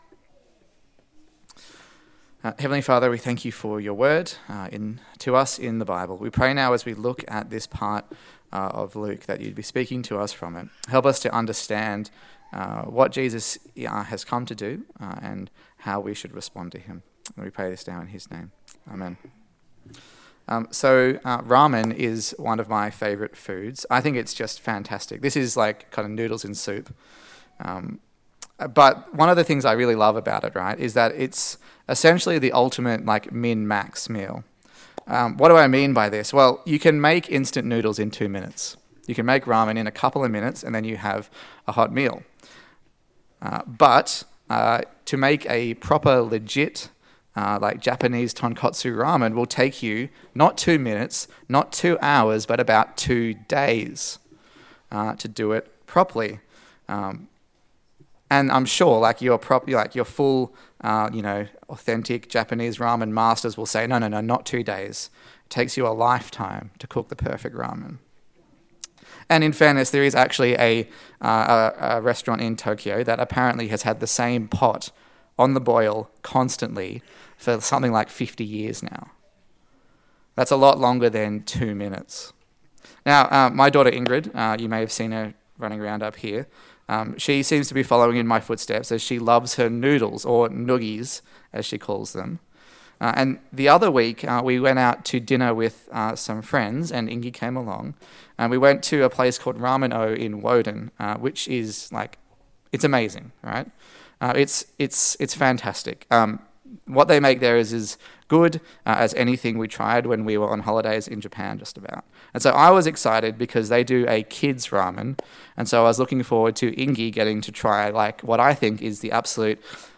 Luke Passage: Luke 13:10-35 Service Type: Sunday Service